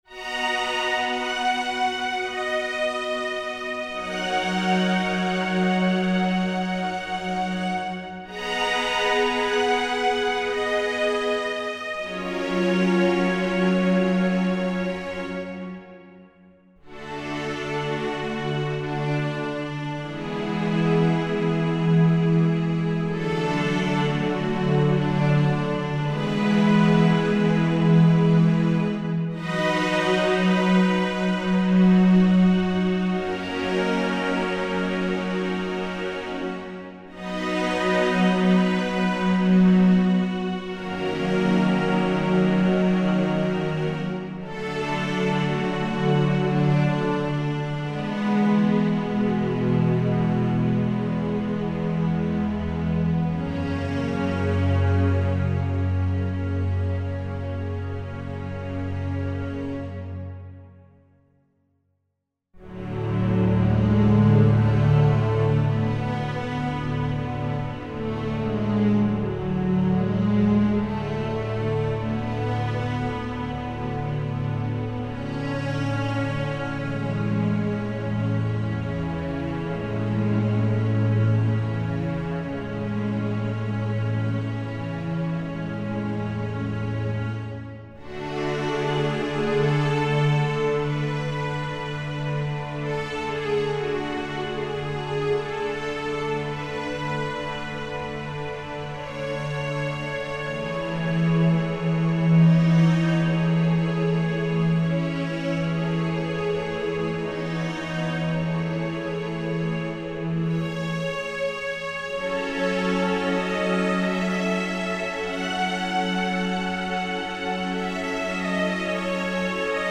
Contemplative strings with quiet longing.
Tempo (BPM) 96